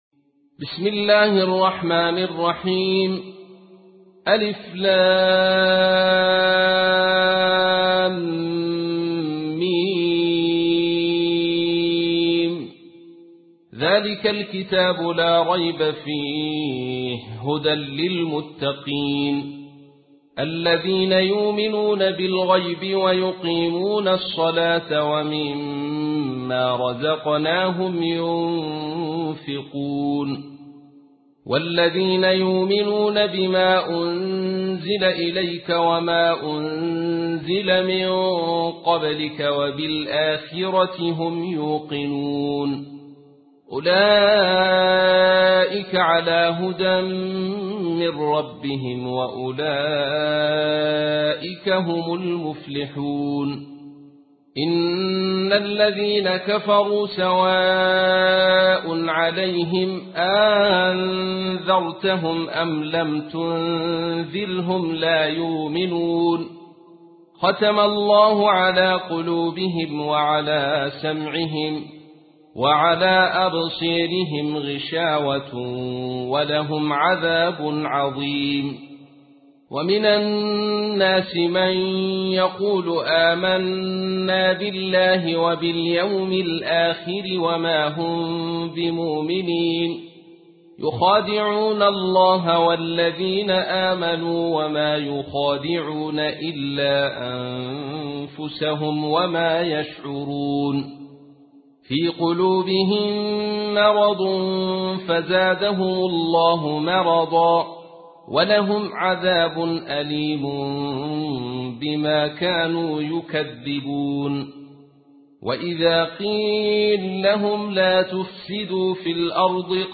تحميل : 2. سورة البقرة / القارئ عبد الرشيد صوفي / القرآن الكريم / موقع يا حسين